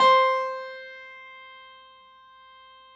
53h-pno14-C3.aif